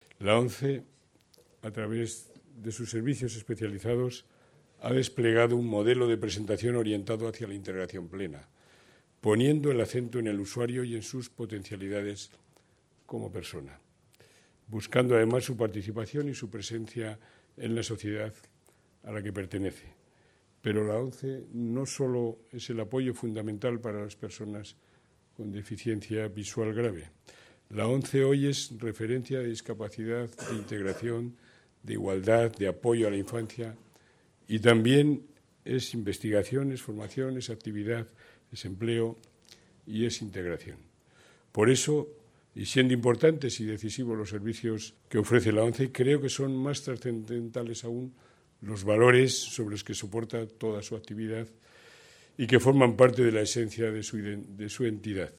Junto a ellos, el jefe del Ejecutivo riojano, José Ignacio Ceniceros, pronunció el discurso oficial de apertura, en el que ensalzó a la ONCE como “referencia para todos” en materia de solidaridad, igualdad, empleo, etc. “Pero, siendo importantes sus servicios, me parecen aún más trascendentes los valores de solidaridad que traslada a toda la sociedad”,